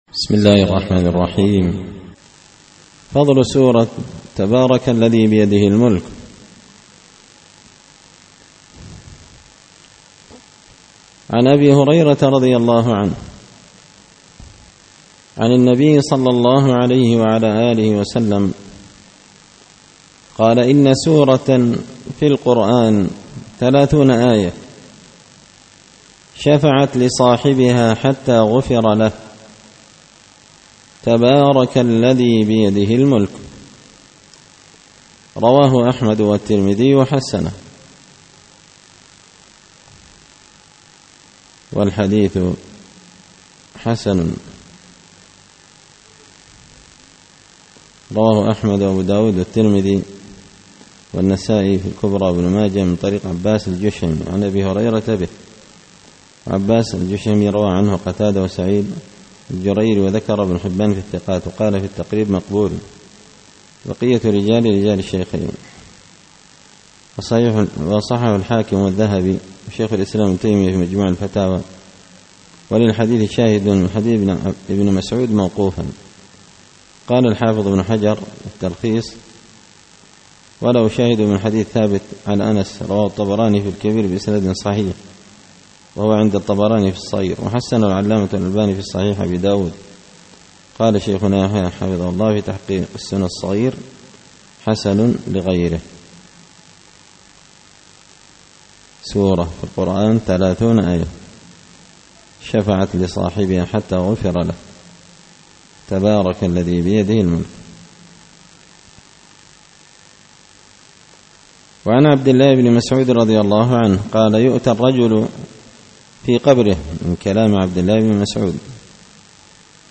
الأحاديث الحسان فيما صح من فضائل سور القرآن ـ الدرس الواحد والأربعون
دار الحديث بمسجد الفرقان ـ قشن ـ المهرة ـ اليمن